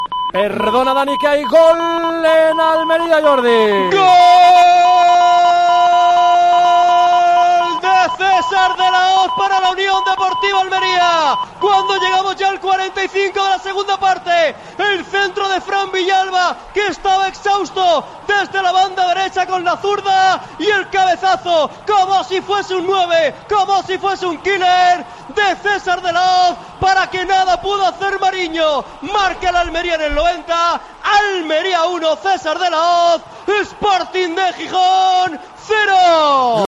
Así se vivió en Tiempo de Juego el tanto marcado por César de la Hoz, en el minuto 90, que le daba la victoria a los almerienses ante el Sporting de Gijón.